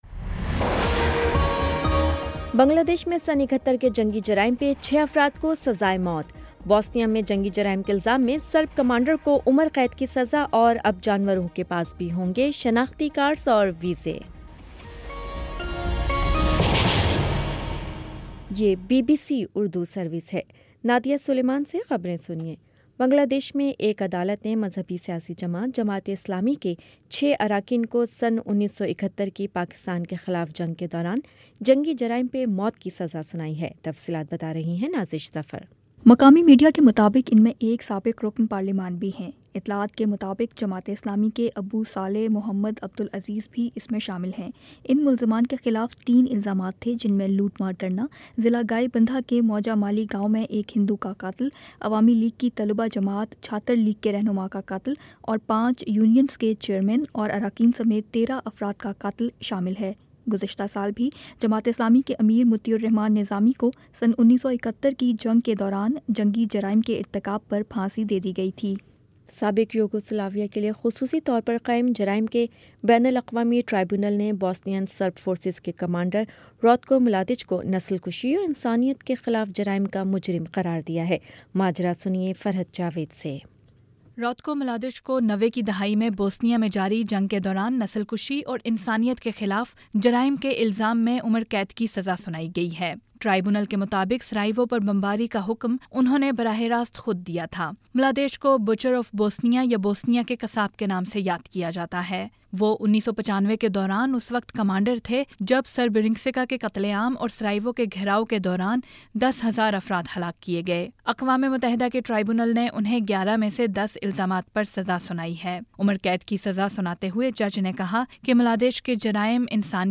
نومبر22 : شام سات بجے کا نیوز بُلیٹن